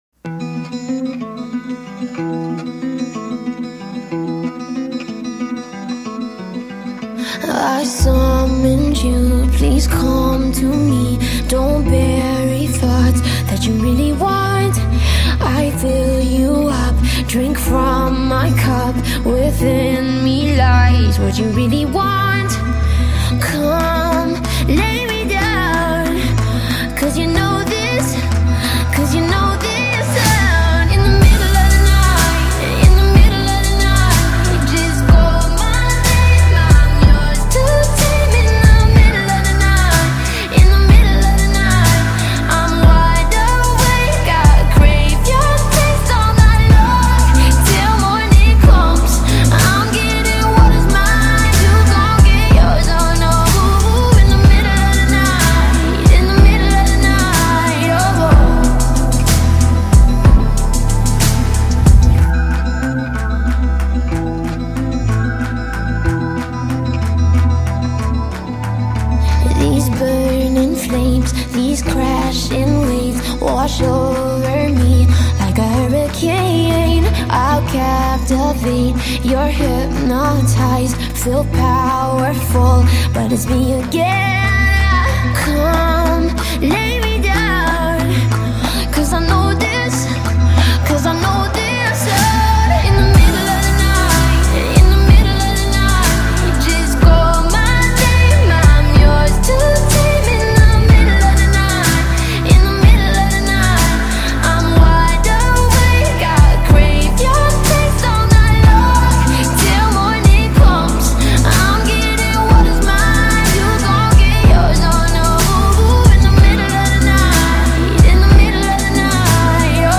ریمیکس آهسته